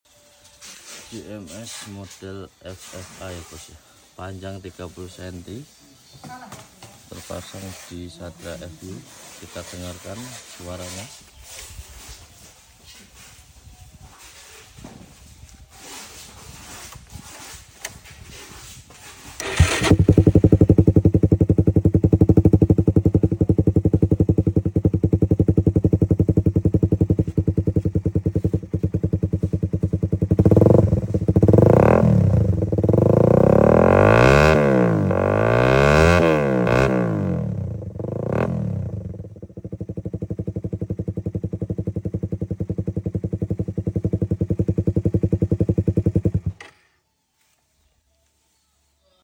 Cek sound knalpot jms ffa sound effects free download
Cek sound knalpot jms ffa p 30 cm inlet 50mm